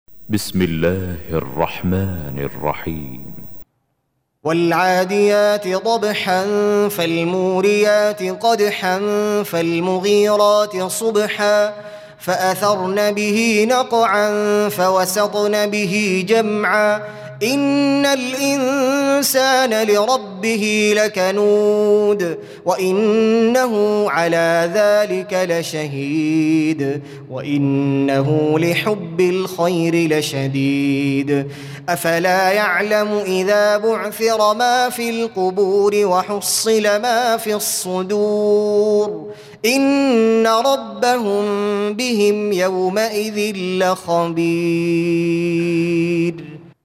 Audio Quran Tarteel Recitation
Surah Repeating تكرار السورة Download Surah حمّل السورة Reciting Murattalah Audio for 100. Surah Al-'Adiy�t سورة العاديات N.B *Surah Includes Al-Basmalah Reciters Sequents تتابع التلاوات Reciters Repeats تكرار التلاوات